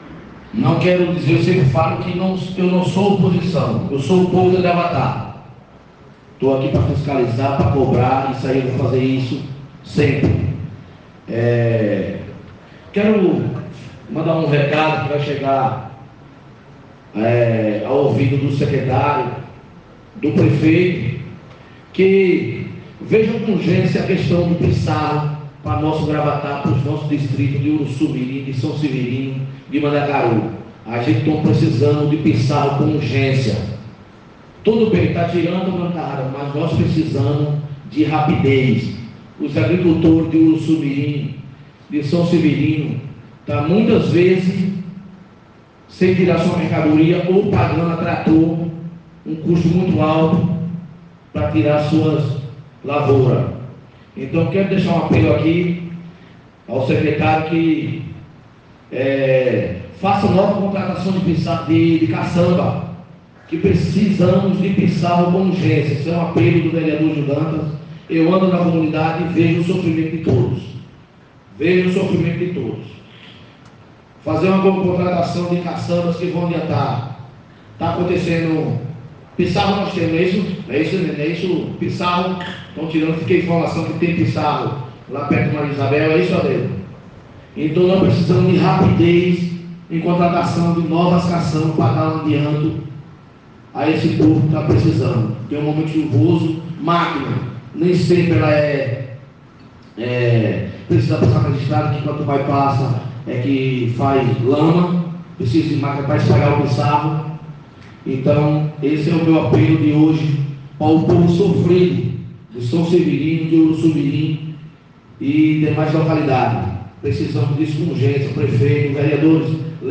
O discurso do vereador Gil Dantas (PSDB) estava bastante afiado durante a primeira reunião presencial da Câmara Municipal de Gravatá, agreste pernambucano, realizada na manhã desta terça-feira (10) na Casa Elias Torres, sede do Poder Legislativo.